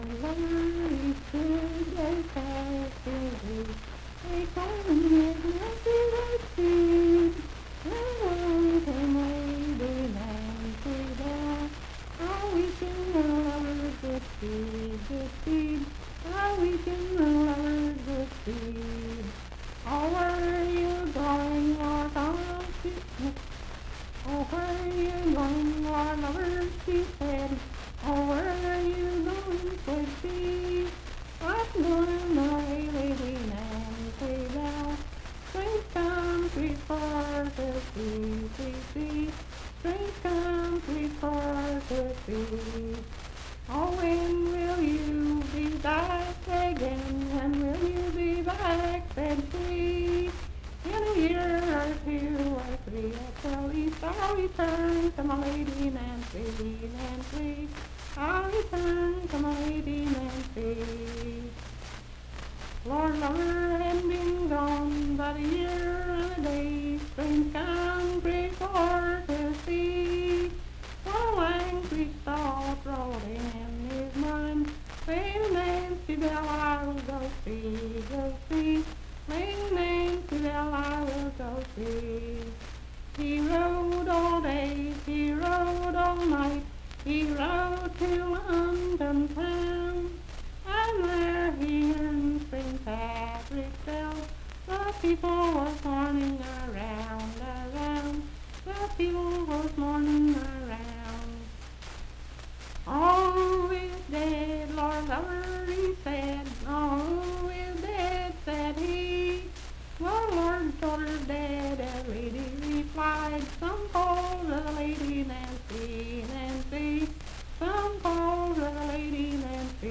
Unaccompanied vocal music
Verse-refrain 8(5w/R).
Voice (sung)